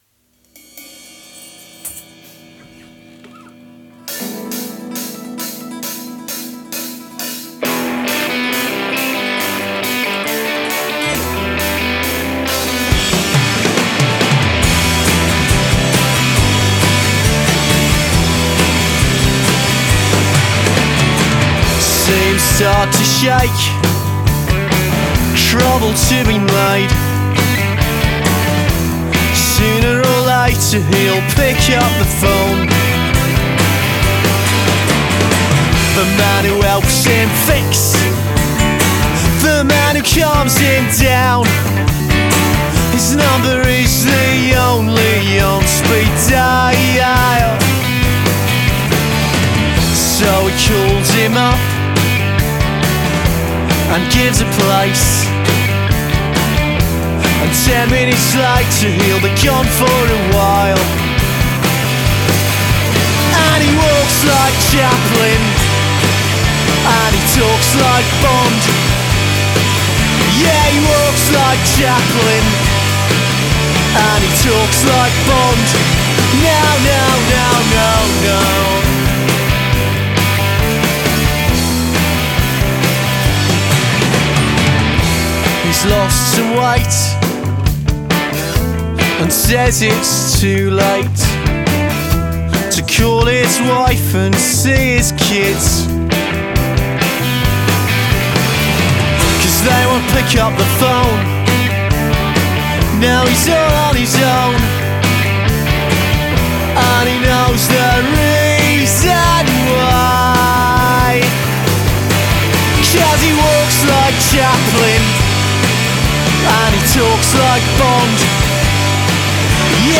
• Genre: Rock / Indie